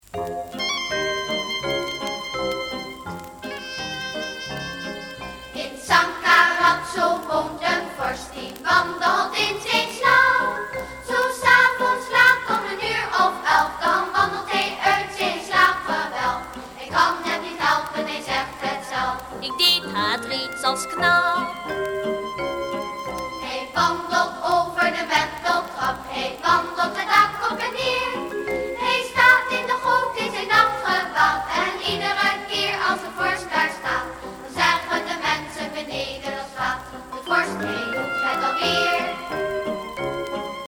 Liedjes